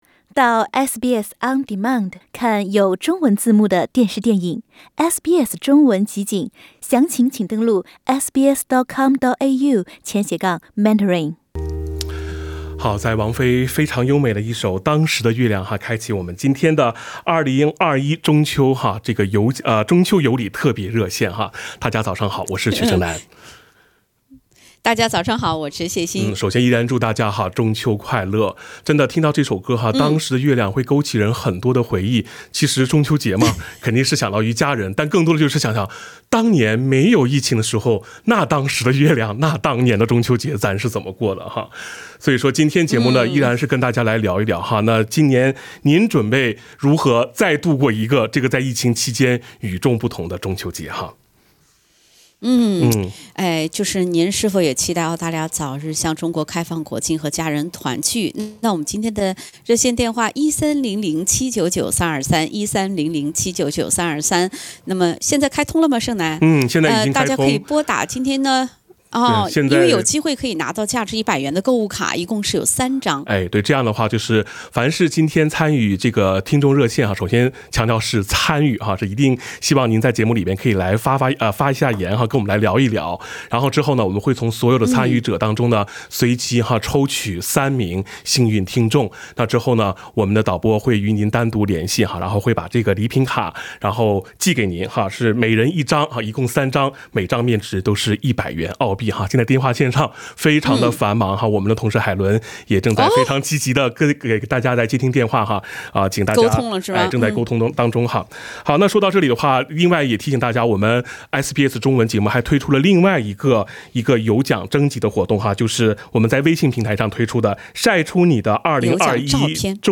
在沒有疫情的日子裡，每年中秋我們都與您在戶外廣播中準時相約；疫情噹前，讓我們再次通過電波寄托對親朋的思念，分享故事、傳遞祝福，表達對早日開放國境，與家人糰聚的期待。